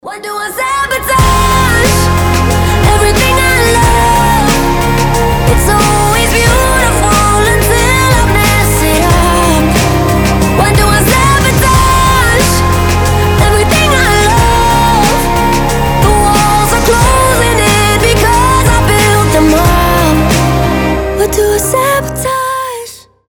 • Качество: 320, Stereo
сильные
красивый вокал